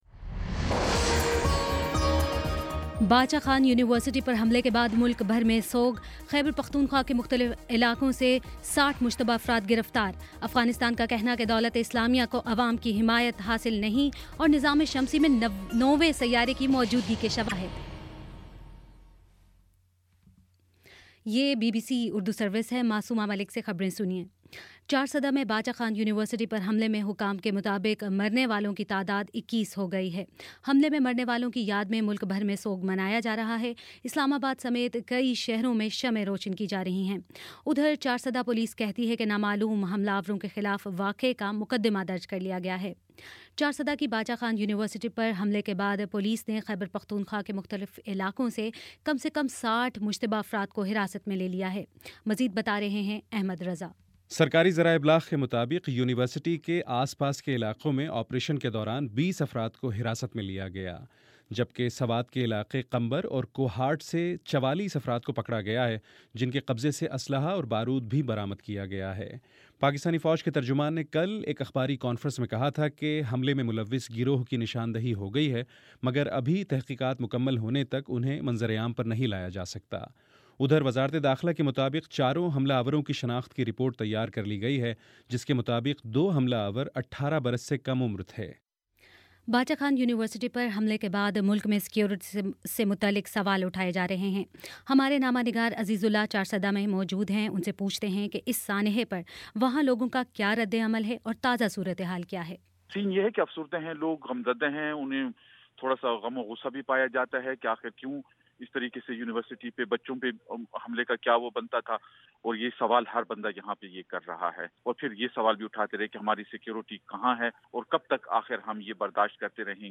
جنوری 21 : شام پانچ بجے کا نیوز بُلیٹن